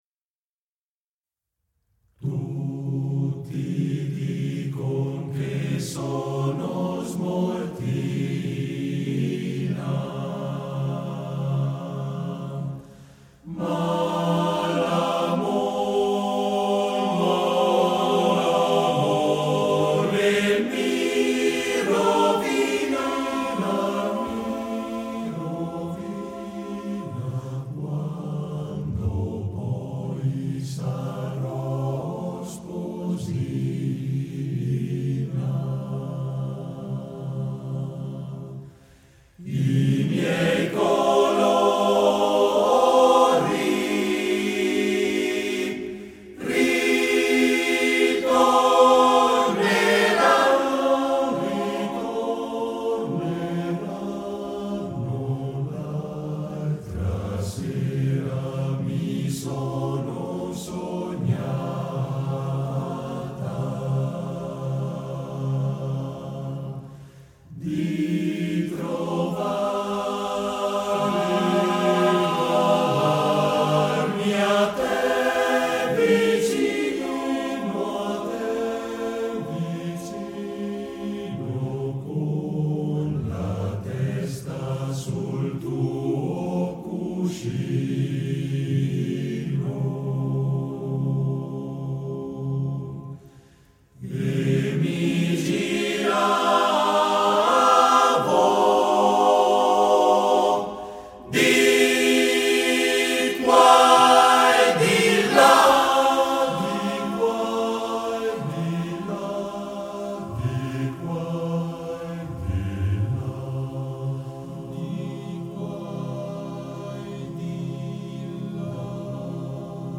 Esecutore: Coro della SAT